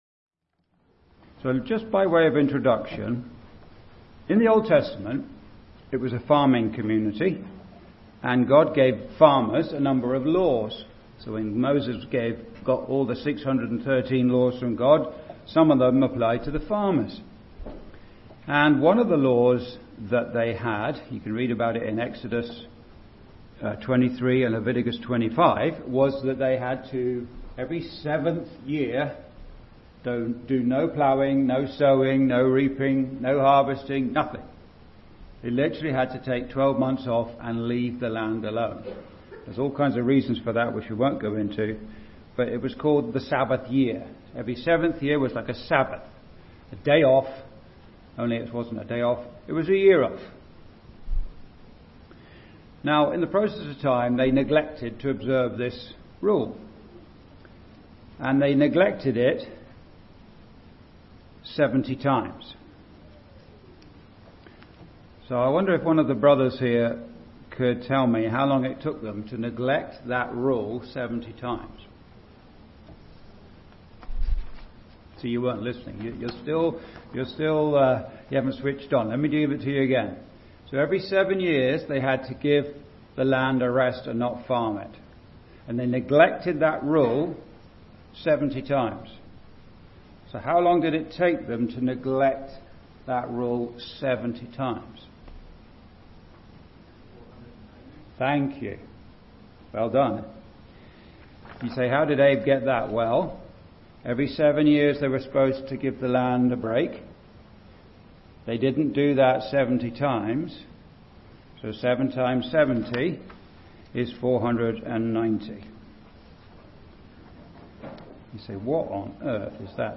The chart accompanying this sermon is available below.
(Recorded in Straffordville Gospel Hall, ON, Canada, on 8th Jan 2026)